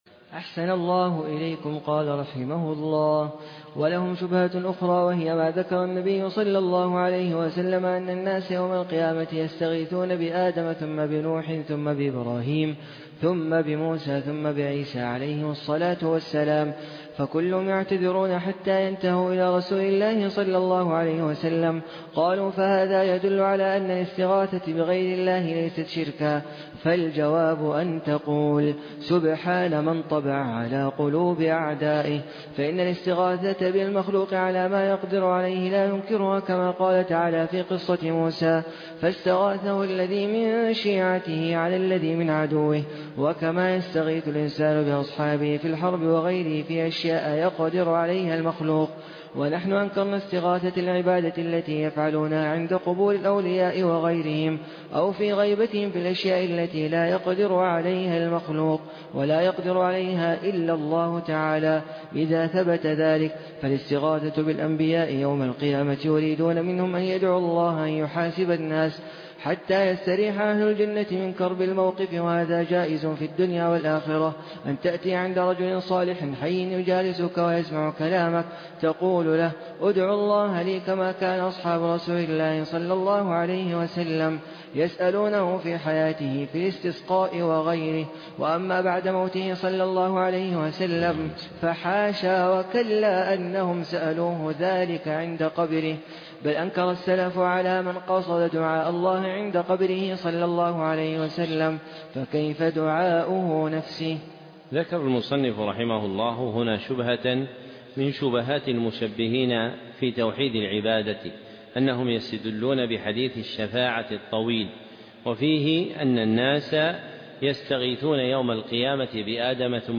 شرح كشف الشبهات الدرس 17